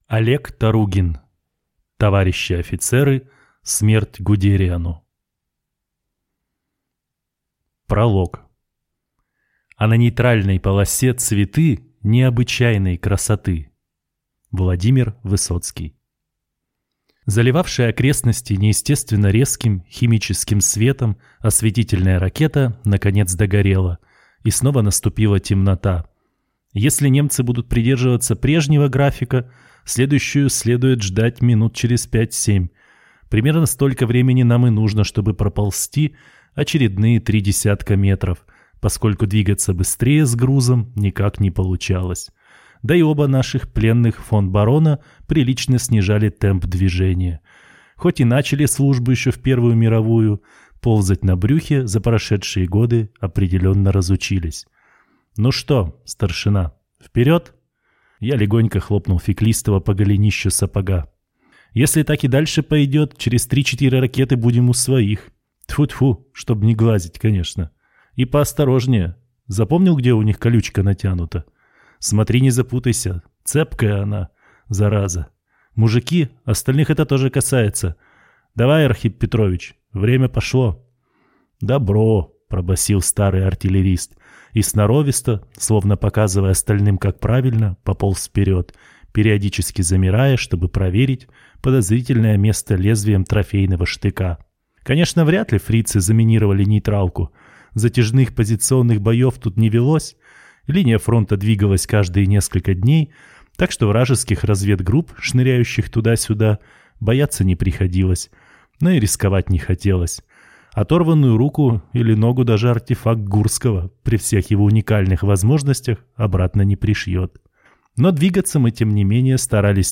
Аудиокнига Товарищи офицеры. Смерть Гудериану!